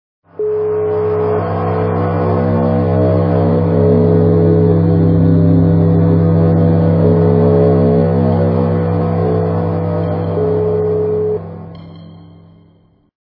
» Звуки » звуки для СМС » Звук для СМС - Тревожный гул
При прослушивании Звук для СМС - Тревожный гул качество понижено и присутствуют гудки.